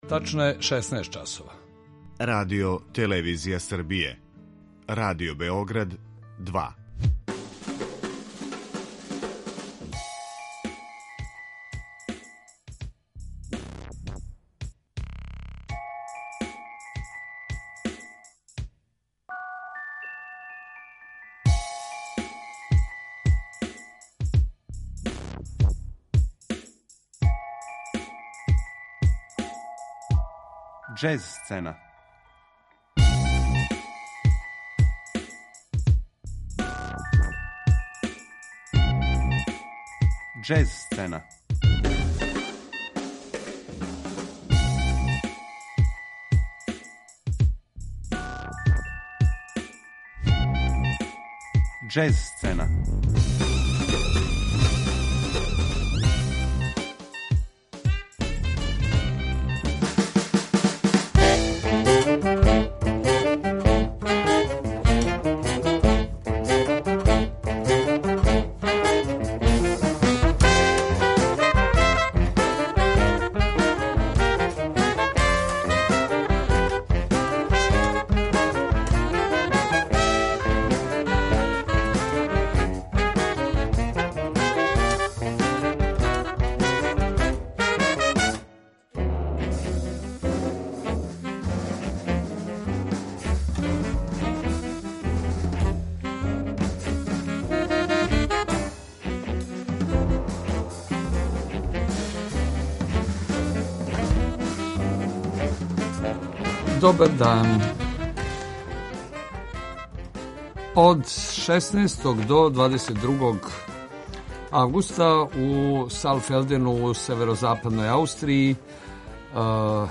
Причу о фестивалу звучно илуструјемо актуелним снимцима учесника ове манифестације.